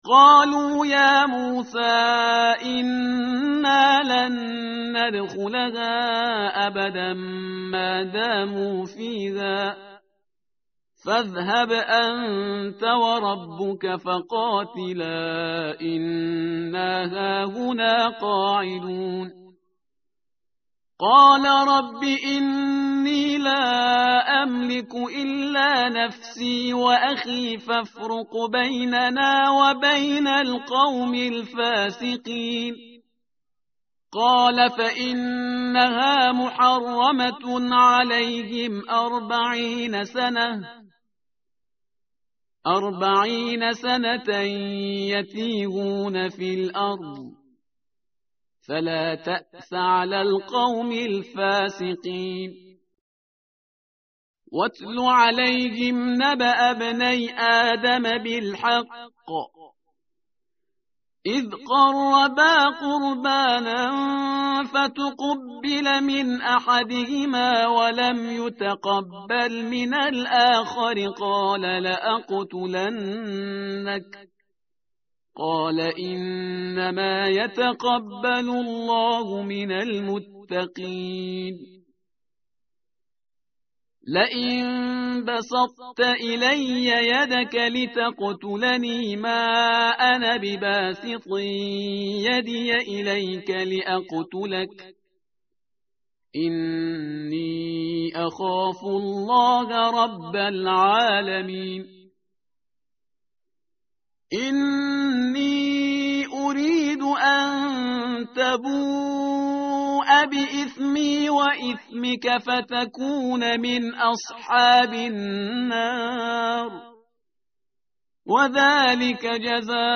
متن قرآن همراه باتلاوت قرآن و ترجمه
tartil_parhizgar_page_112.mp3